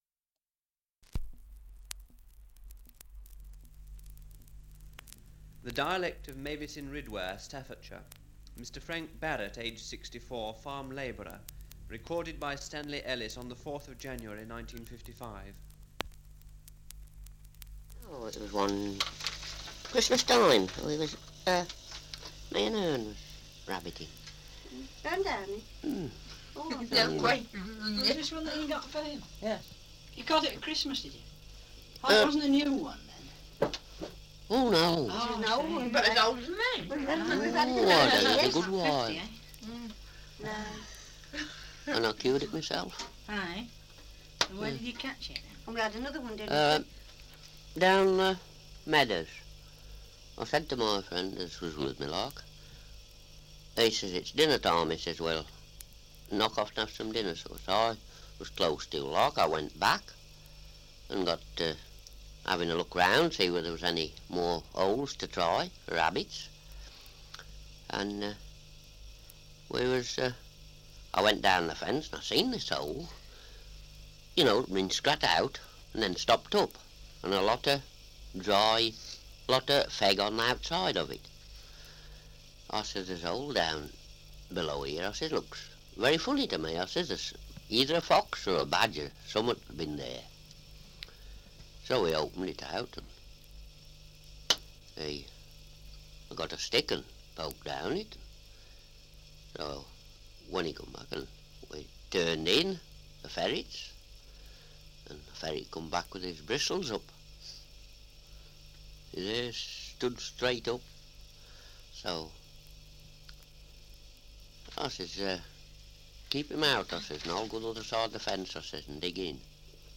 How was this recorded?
Survey of English Dialects recording in Mavesyn Ridware, Staffordshire 78 r.p.m., cellulose nitrate on aluminium